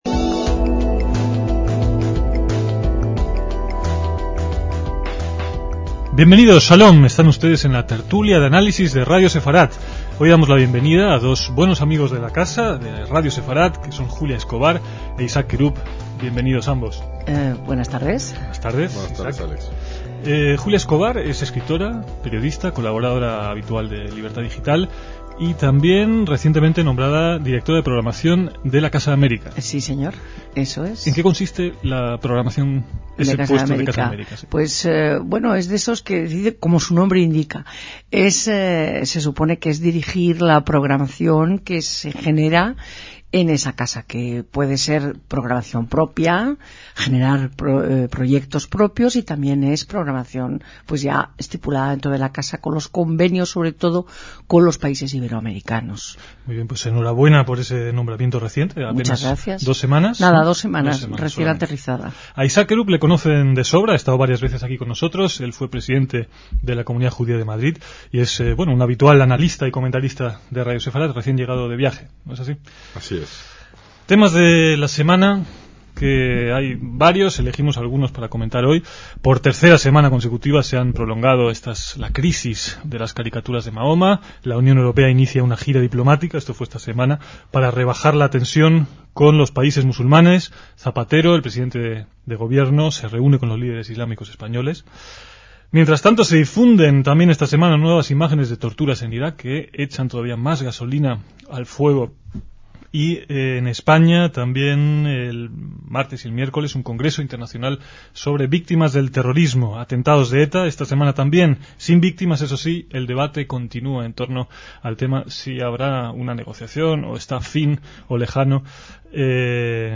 debaten en torno a la actualidad informativa